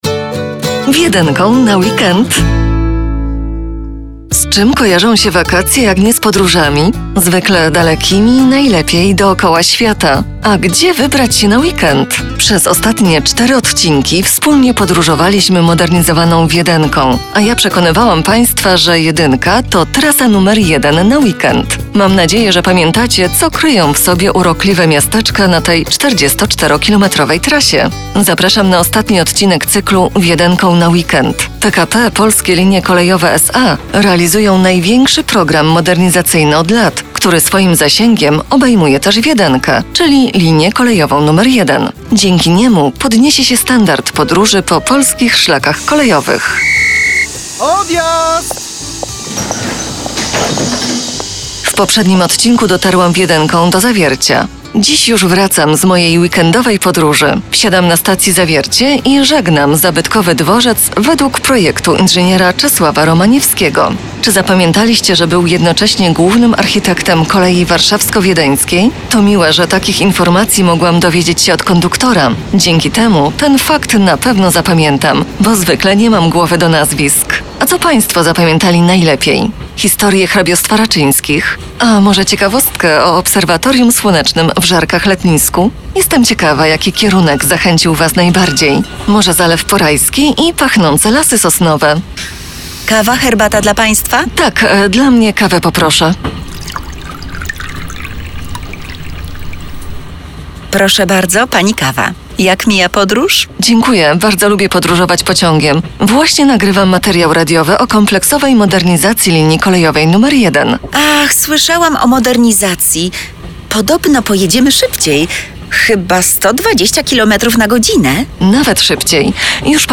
Cykl audycji radiowych - czerwiec 2018 r.